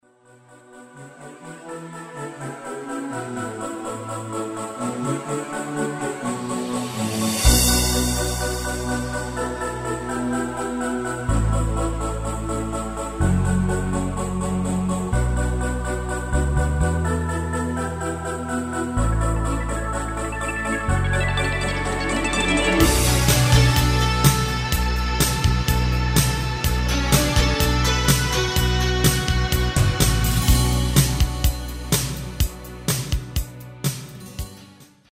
Demo/Koop midifile
Genre: Evergreens & oldies
- Géén vocal harmony tracks
Demo = Demo midifile